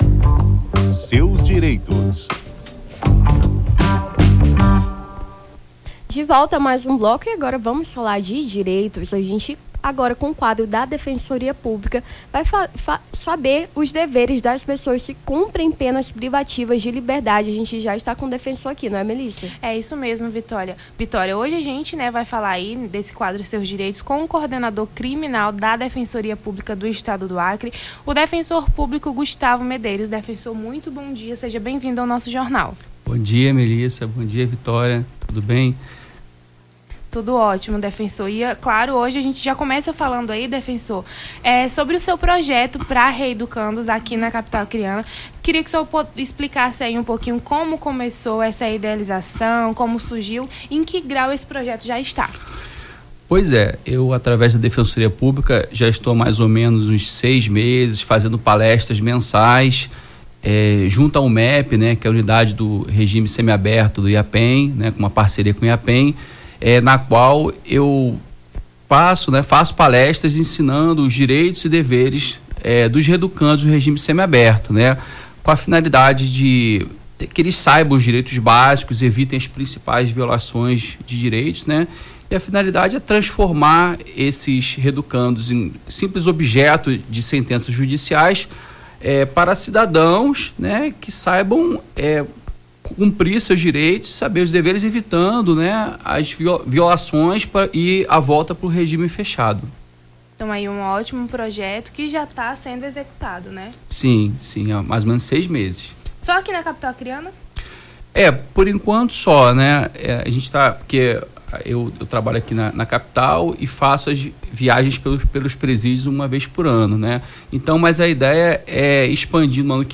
No Jornal da Manhã desta segunda-feira(27), as apresentadoras